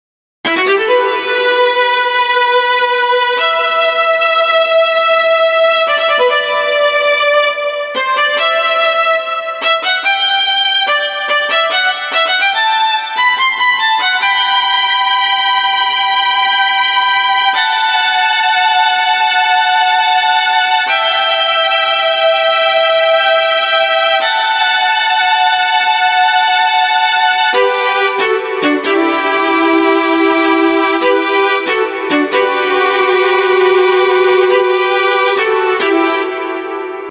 HALion6 : Studio Strings